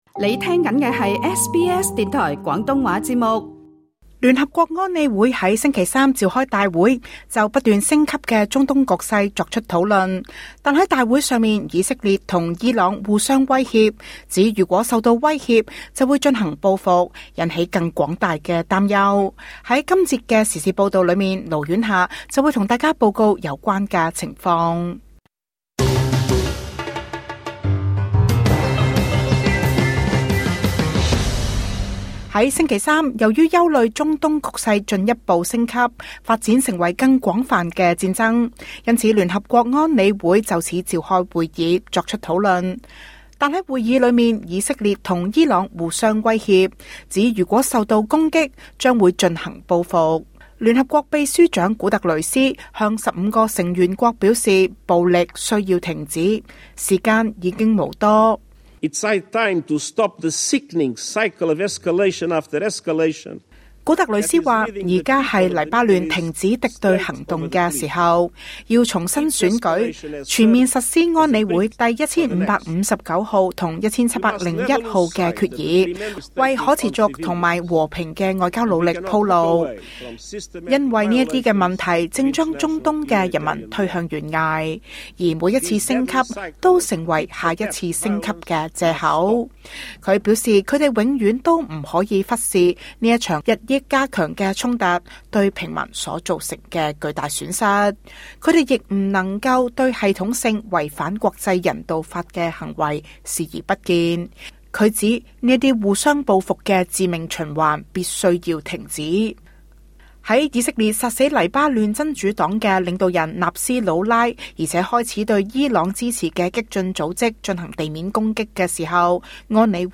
在今節的時事報道中